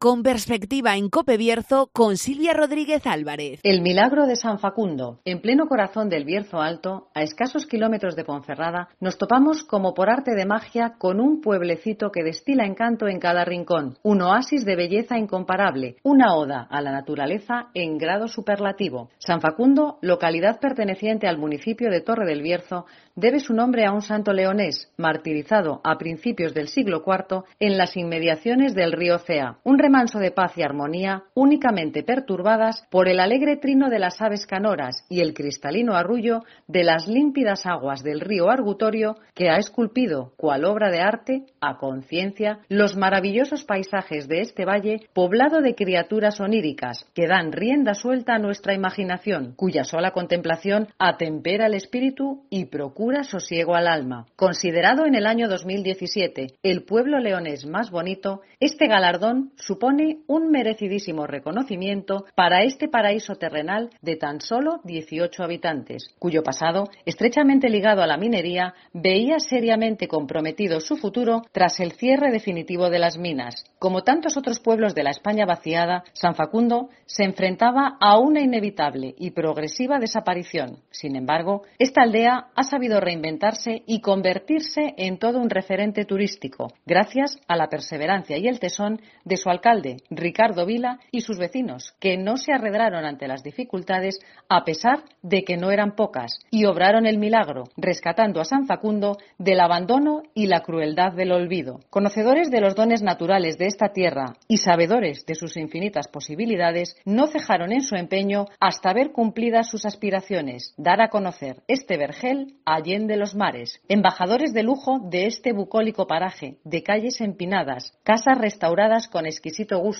OPINIÓN-SOCIEDAD